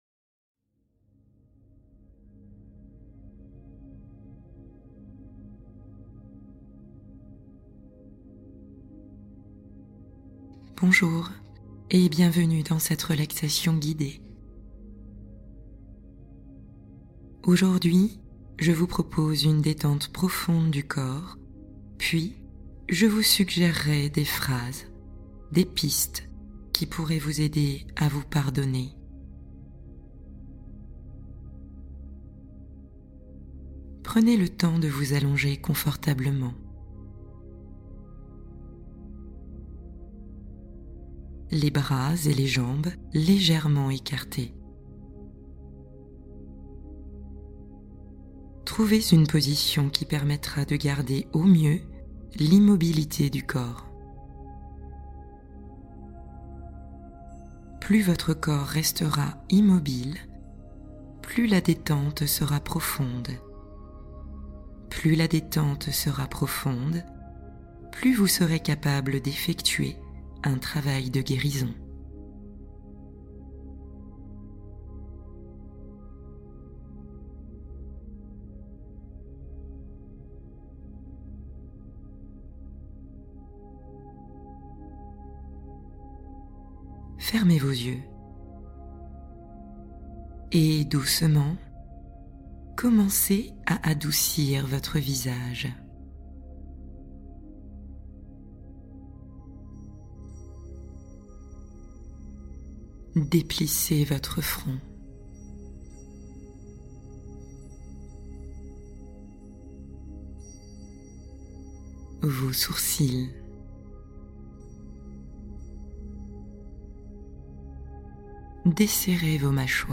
Pardonnez-vous enfin et respirez librement | Méditation de guérison intérieure